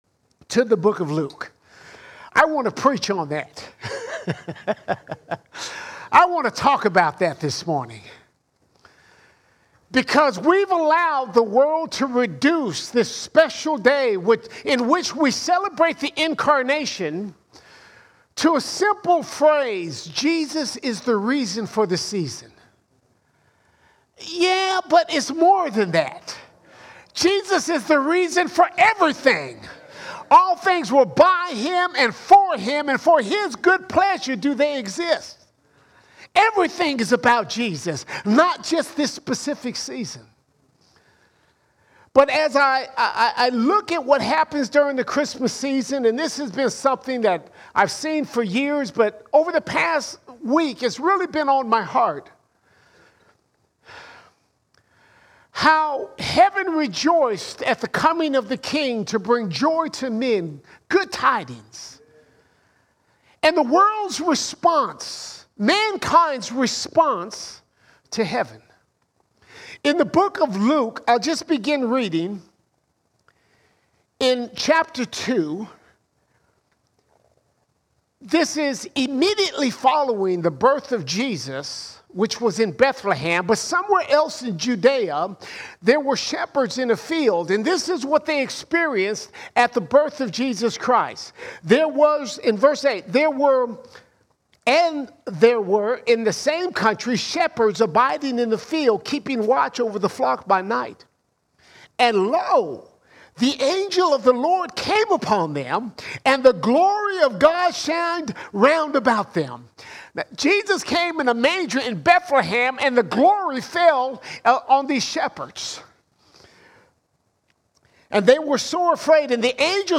26 December 2023 Series: Sunday Sermons Topic: christmas All Sermons When God Came to Earth When God Came to Earth Jesus is not just the "reason for the season"; He is the reason for everything.